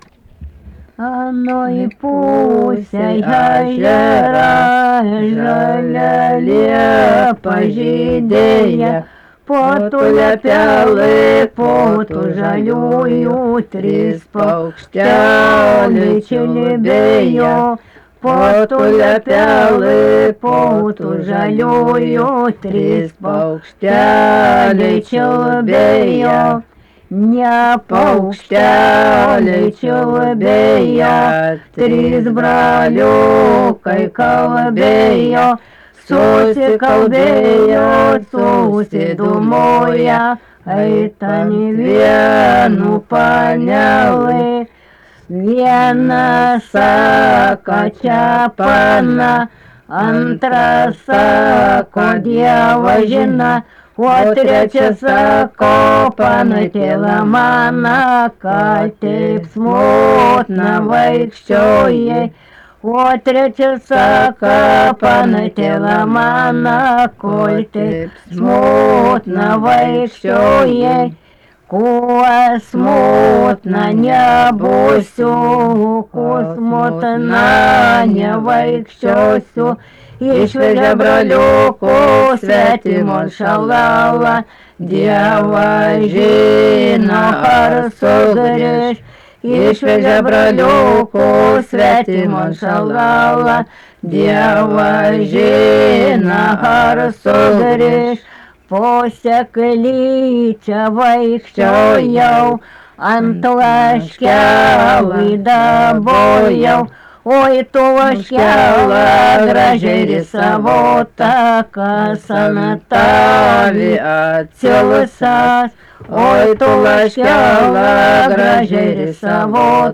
daina
Krapiškis
vokalinis